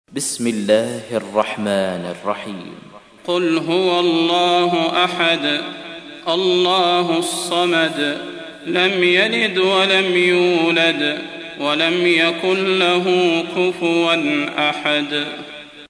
تحميل : 112. سورة الإخلاص / القارئ صلاح البدير / القرآن الكريم / موقع يا حسين